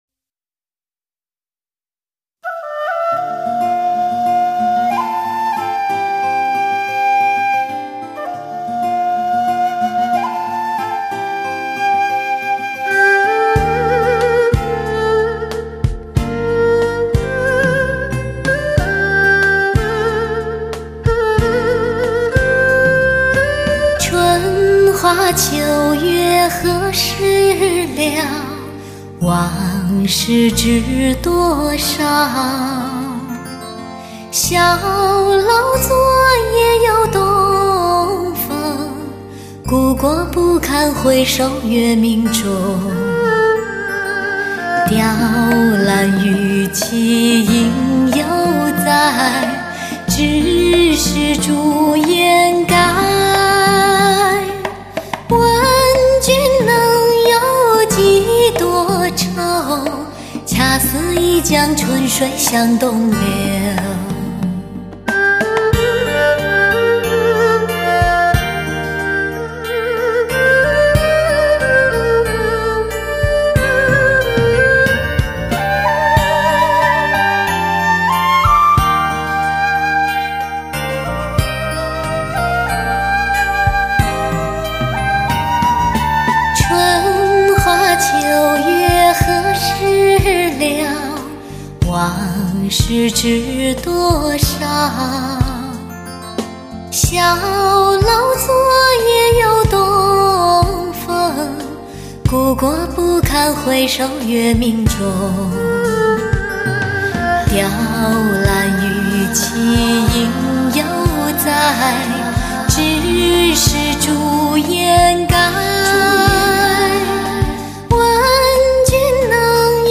类型: HIFI试音
唱片介绍钻石精选版，发烧女声终极靓声版；本CD母盘采用K2HD编码技术处理；K2HD高解析母盘编解码